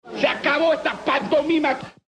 pant.mp3